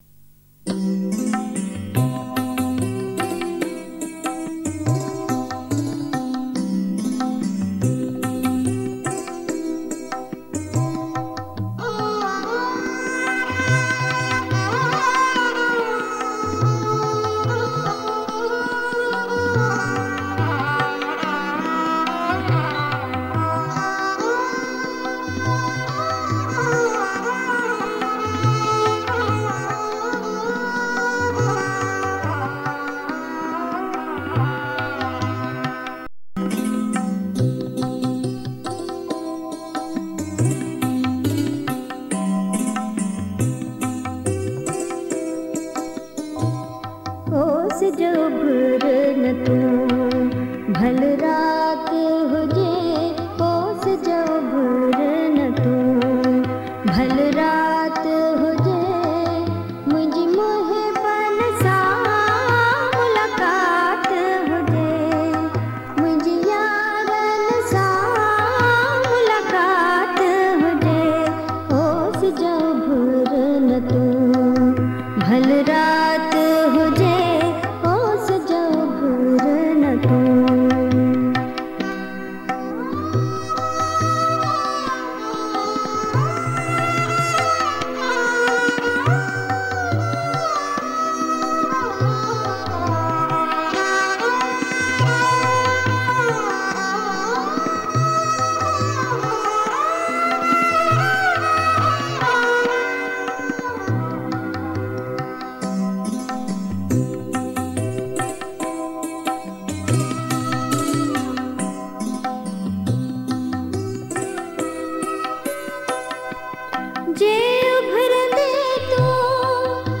Sindhi Geet and Kalam